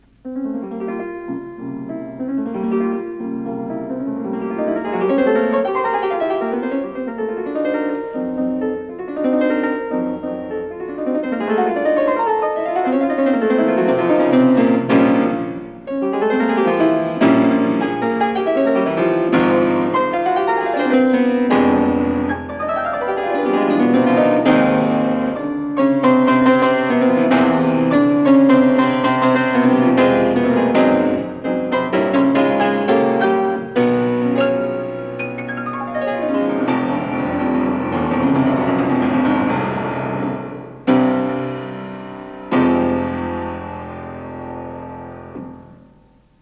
The analyzed Chopin performances were audio recordings played by 5 famous pianists:
Emil Gilels recorded in 1953